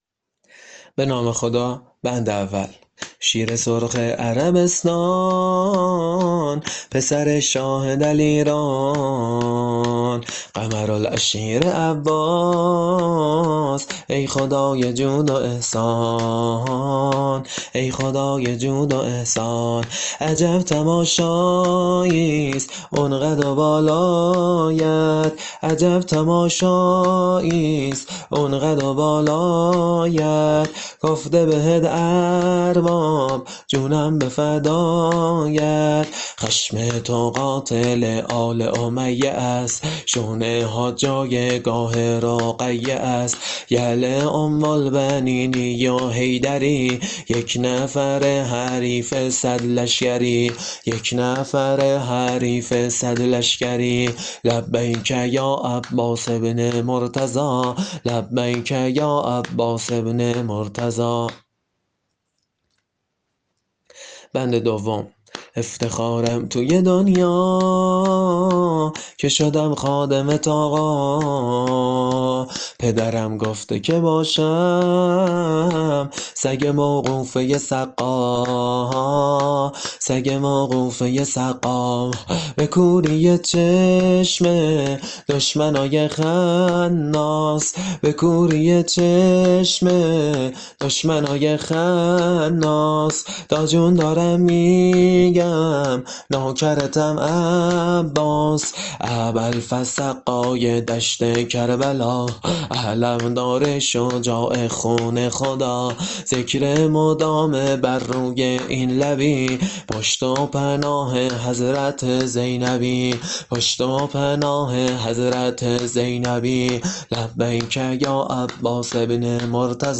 شور حضرت ابالفضل (ع) -(شیرِ سُرخِ عربستان ، پسره شاهه دلیران)